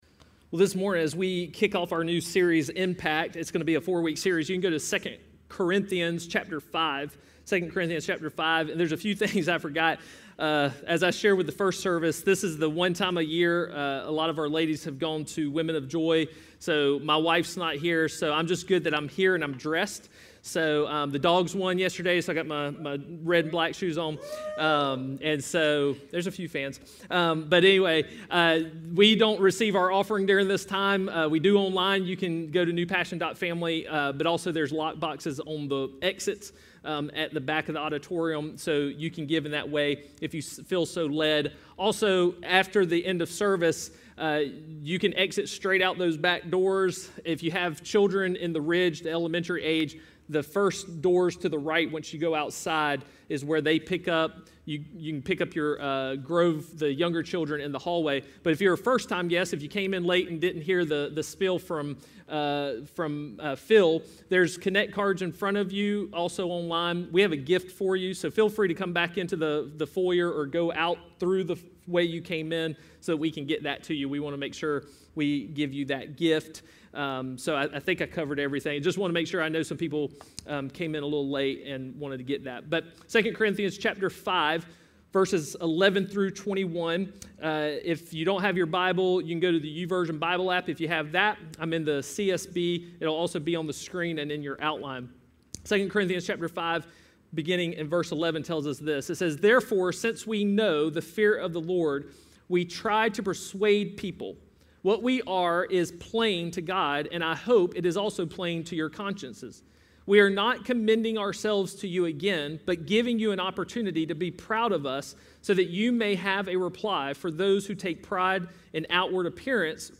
A message from the series "Disciple."